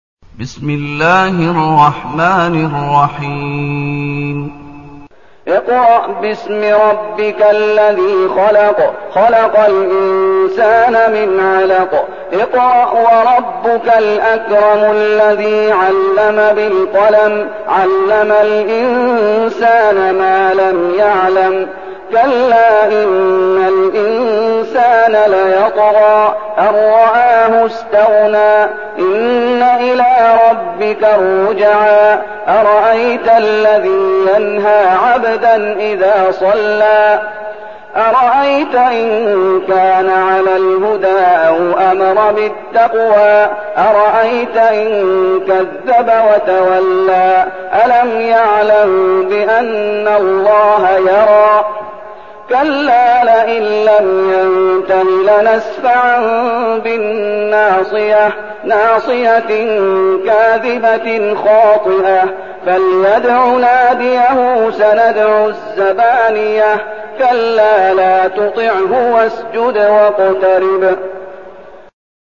المكان: المسجد النبوي الشيخ: فضيلة الشيخ محمد أيوب فضيلة الشيخ محمد أيوب العلق